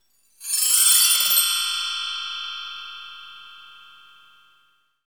PRC BELL 03R.wav